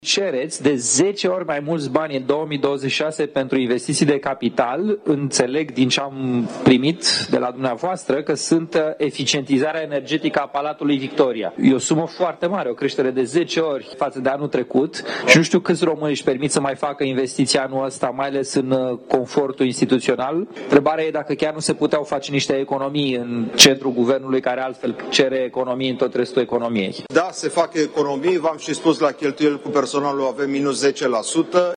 Dezbateri în plină desfășurare la Parlament, în comisiile reunite de buget și finanțe. La această oră, parlamentarii discută despre bugetul alocat Secretariatului General al Guvernului. Deputatul USR, Claudiu Năsui, i-a reproșat secretarului general al Guvernului, Radu Oprea, că există fonduri pentru eficientizarea energetică a Palatului Victoria într-o perioadă în care toate instituțiile publice sunt nevoite să facă economii.
17mar-11-Nasui-si-Oprea-despre-bugetul-SGG.mp3